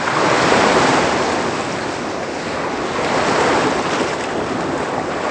Sea.ogg